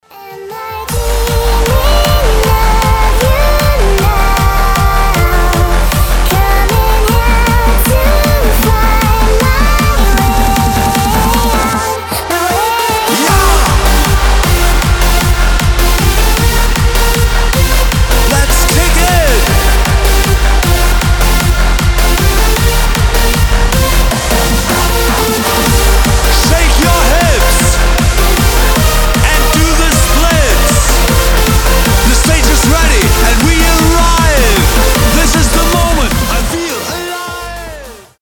happy hardcore
hardstyle , рейв , динамичные
быстрые , энергичные , детский голос , громкие